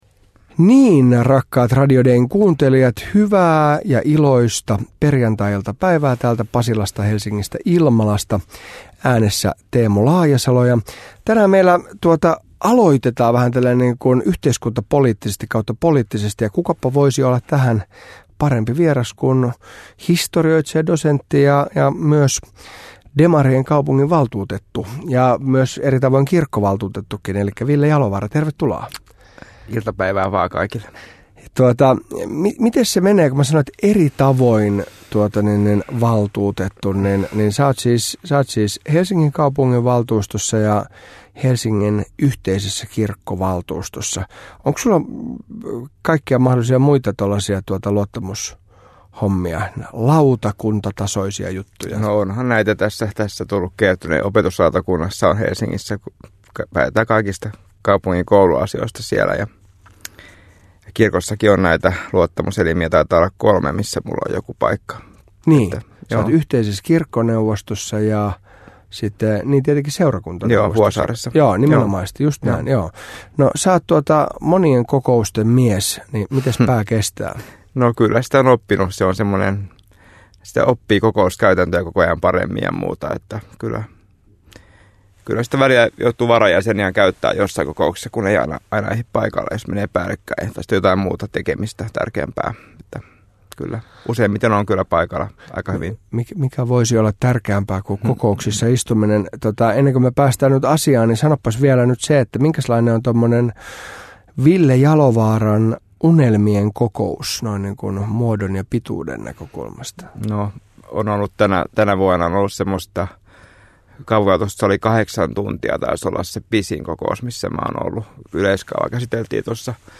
Kallion seurakunnan kirkkoherra Teemu Laajasalo heittäytyy hetkeksi poliittiseksi toimittajaksi. Onko poliittinen vääntö kovempaa puolueiden sisällä vai ulkopuolella?
Vieraana on historoitsija, helsinkiläinen kaupunginvaltuutettu Ville Jalovaara.